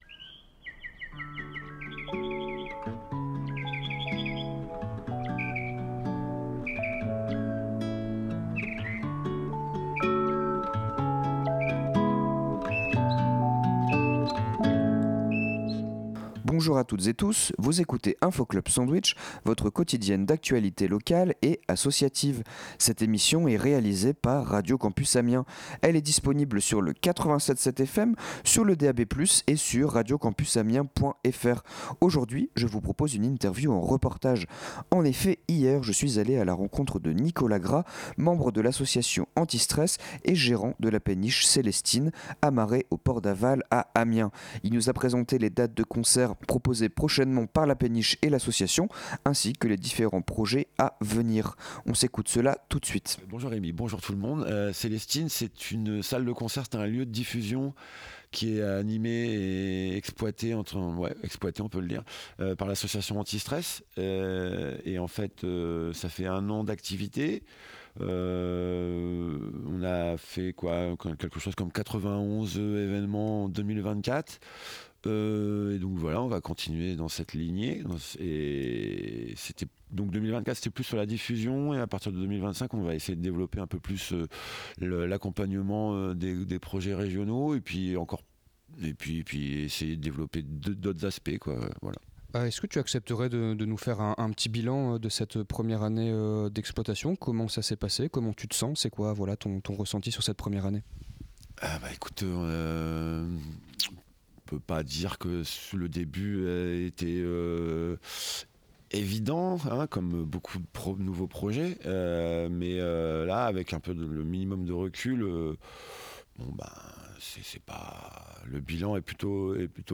Aujourd’hui nous vous proposons une émission deux en un avec dans un premier temps l’interview
Côté musique, on s’écoute deux realeases hip-hop amiénoises